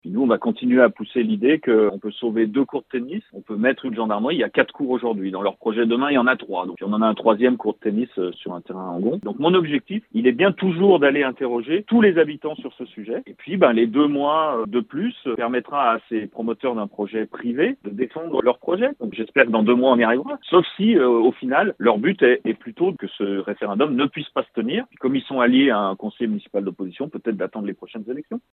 La réaction de Didier Sarda, le maire de Talloires-Montmin :